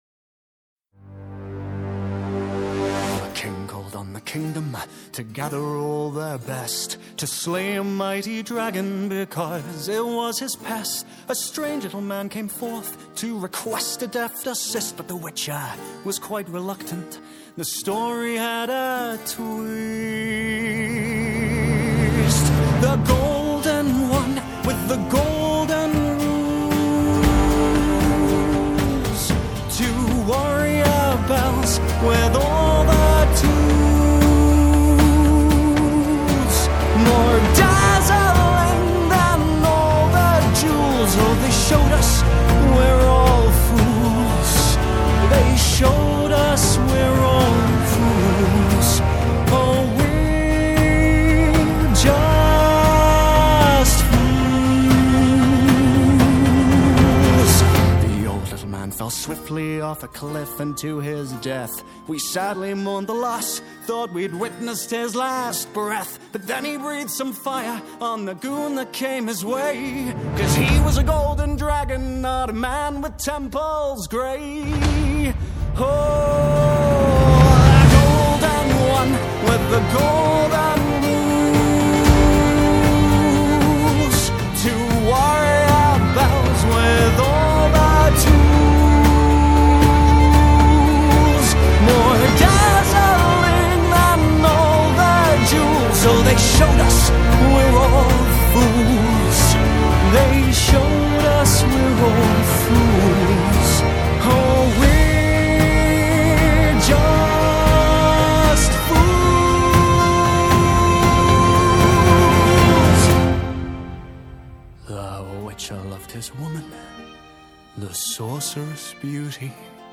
Soundtrack, Fantasy